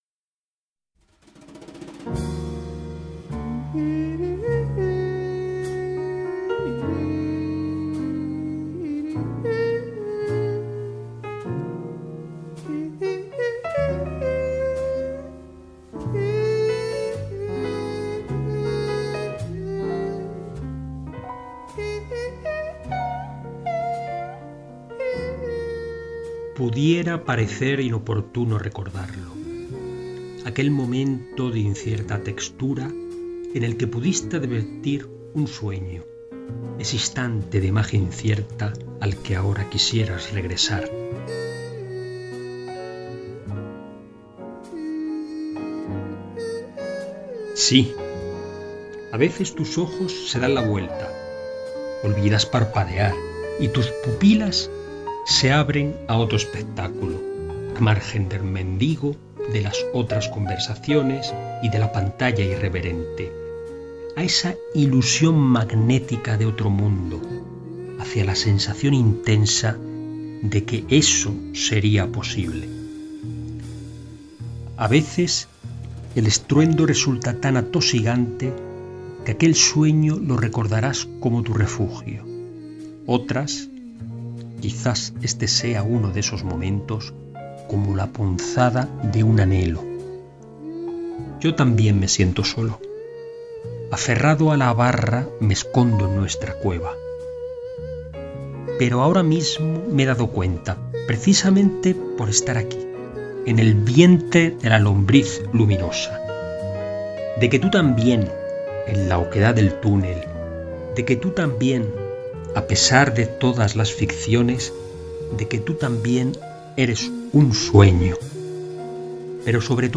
en-el-metro-y-musica.mp3